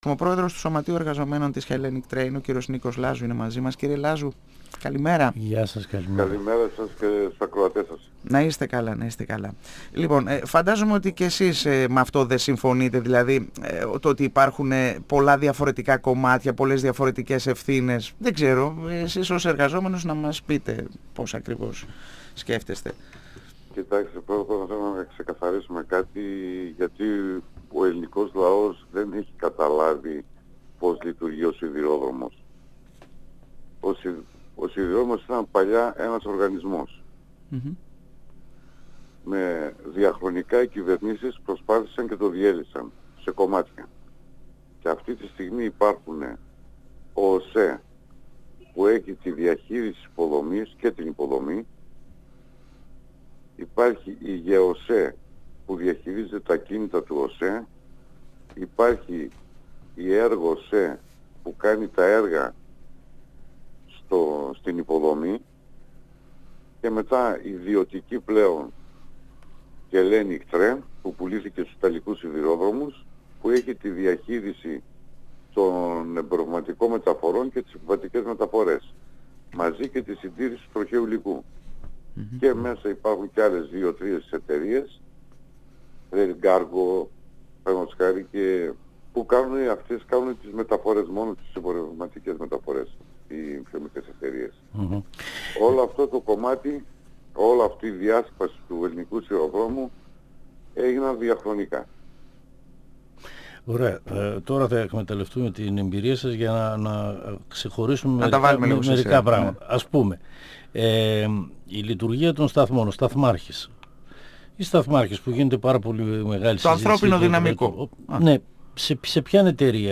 Ο ίδιος ζήτησε να αφήσουμε τη δικαιοσύνη να βγάλει το πόρισμα όπως και τους εμπειρογνώμονες των δύο εταιρειών, της Hellenic και του ΟΣΕ. 102FM Συνεντεύξεις ΕΡΤ3